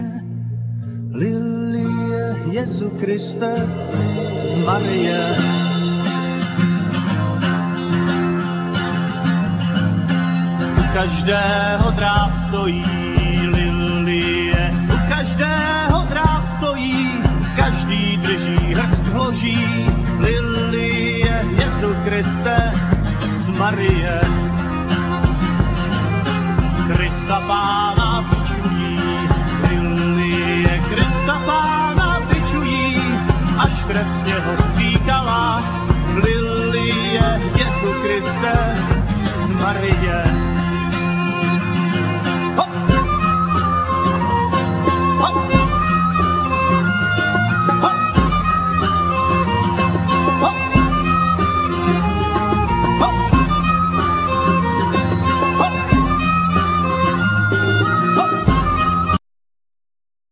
Cena,Flute,Zither
Vocals,Mandolin,Grumle
Violin,Viola
Percussions,Zither,Cymbal
Double-bass
Clarinet
Bell,Percussions